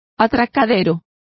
Complete with pronunciation of the translation of berth.